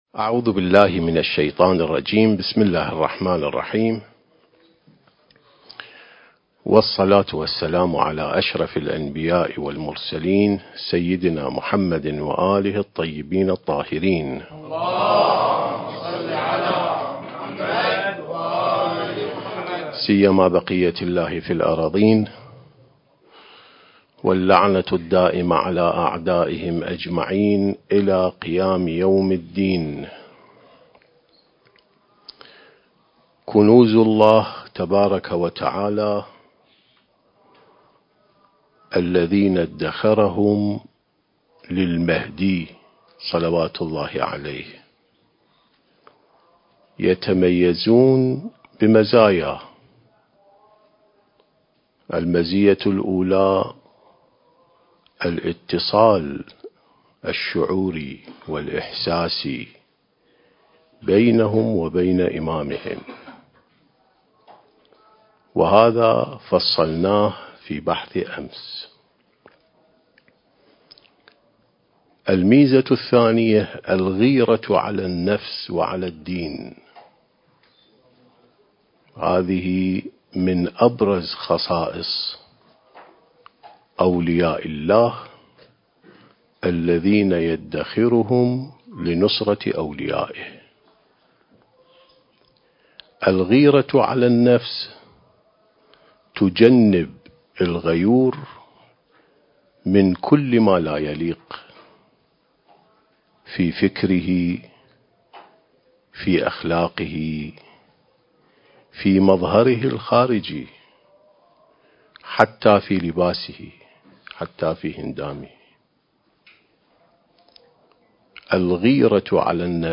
سلسة محاضرات الإعداد للمهدي (عجّل الله فرجه) (7) التاريخ: 1444 للهجرة